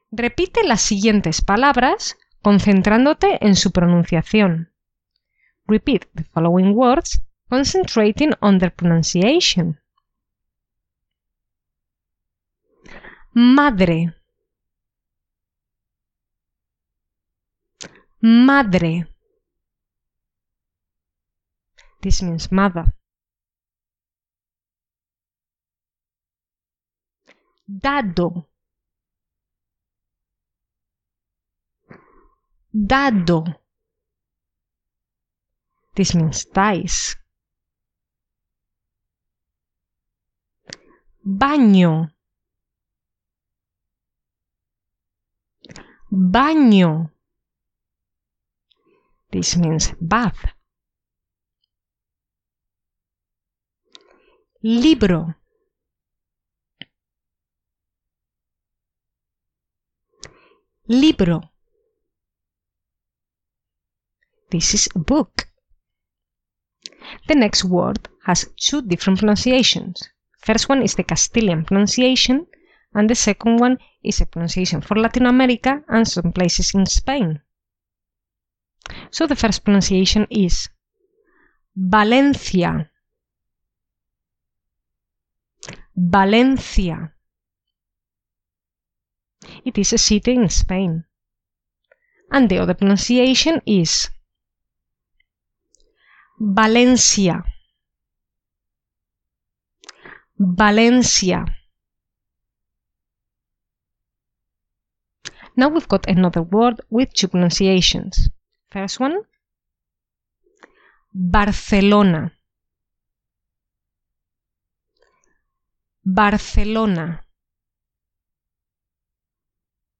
Review